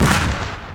FX - Stomp 1.wav